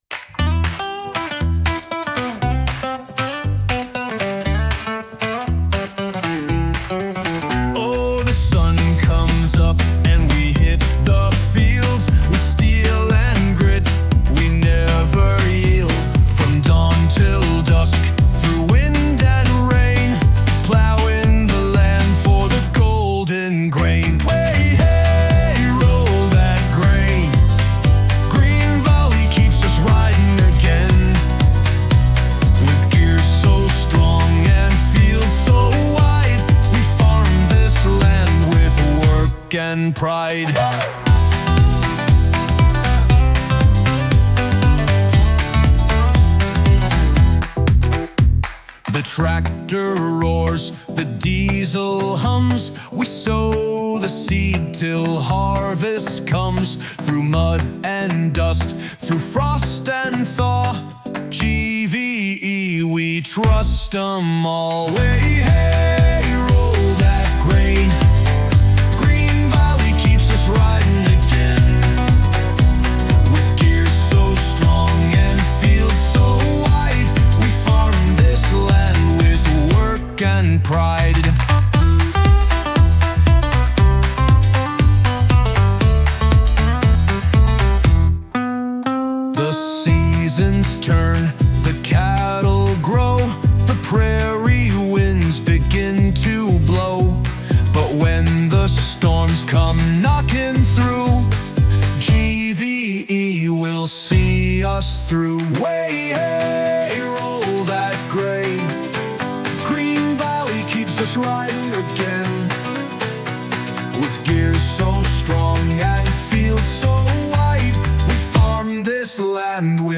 We wrote a prairie-powered anthem that captures what it means to farm, work, and live boldly on the land, and now, we're inviting you to make it your own.
Listen to the anthem (Download Full Anthem (With Vocals)